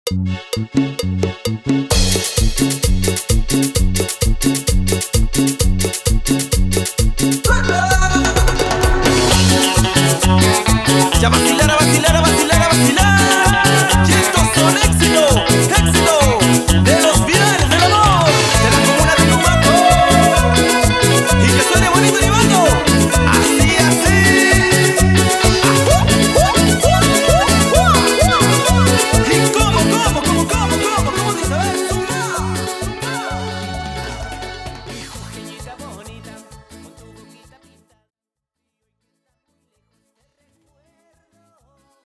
Etiqueta: Cumbia